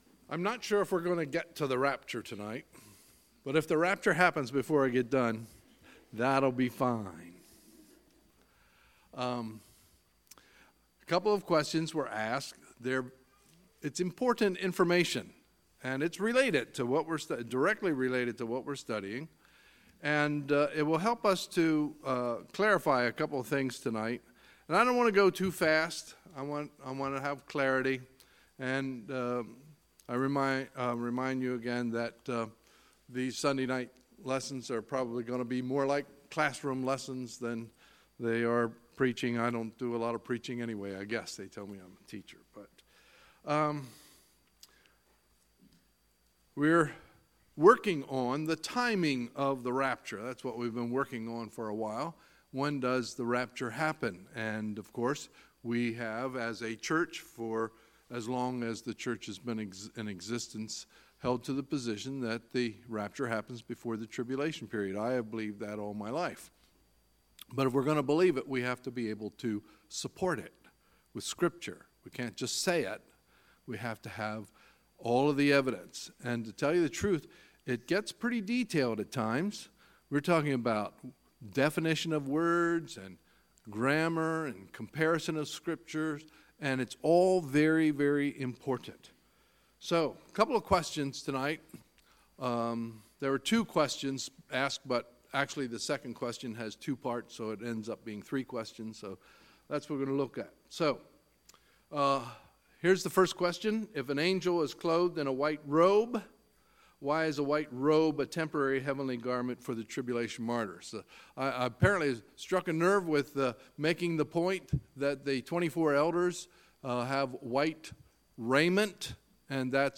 Sunday, May 20, 2018 – Sunday Evening Service